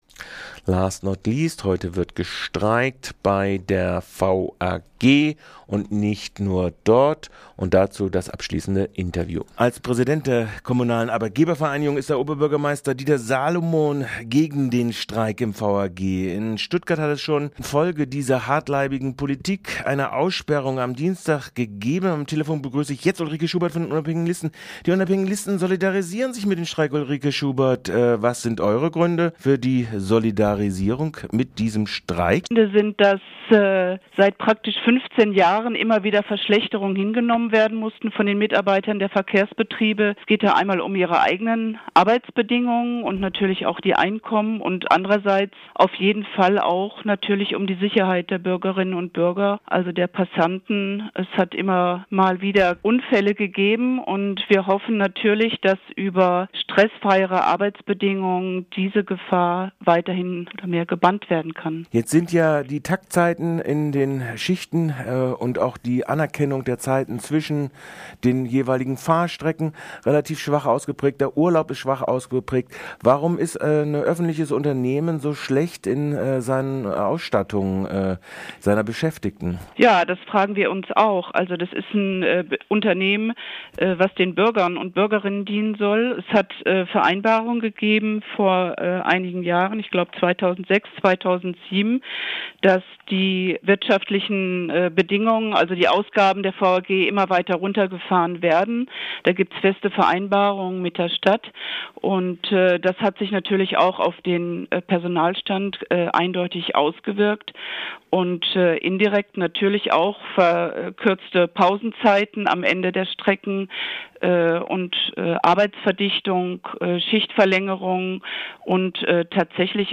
Die UL solidarisiert sich mit den Streiks der kommunalen Verkehrunternehmen insbesondere der VAG. Warum im RDL Morgenradiogespräch.